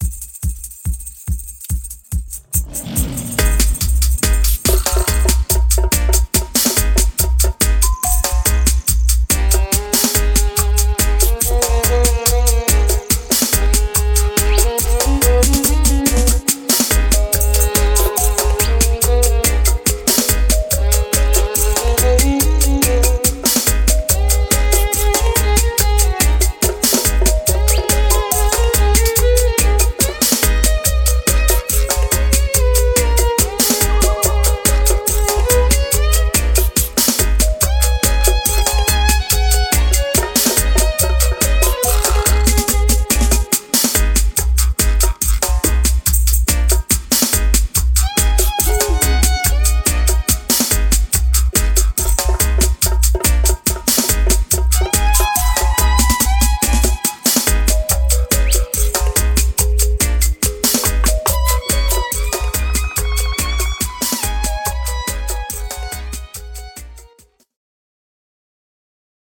Real Dub Roots Reggae